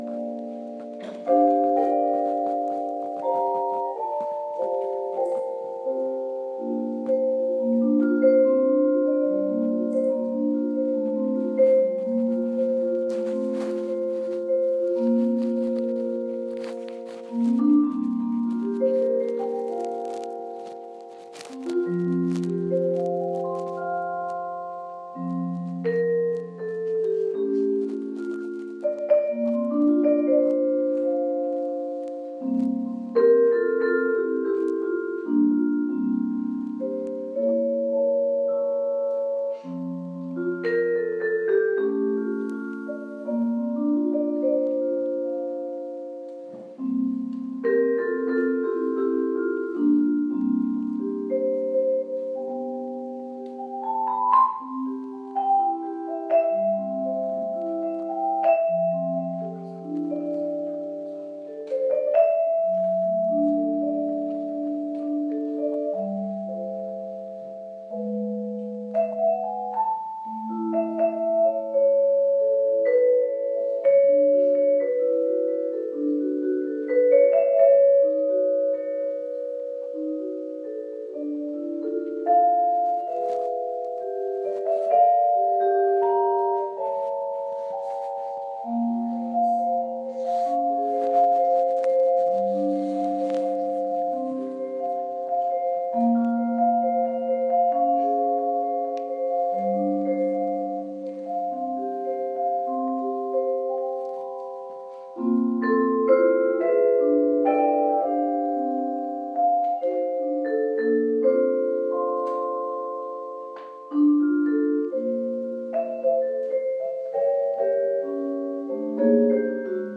concert-jazz-2
concert-jazz-2.m4a